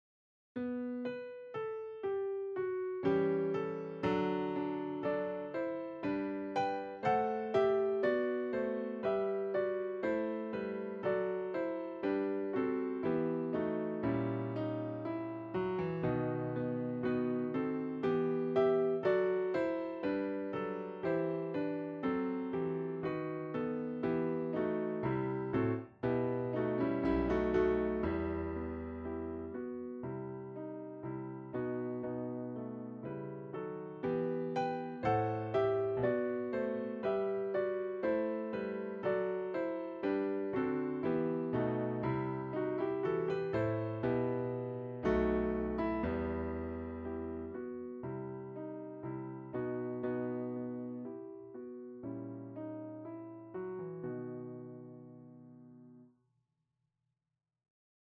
Description MIDI-Version Zitierhilfe
RF251_franzpiano.mp3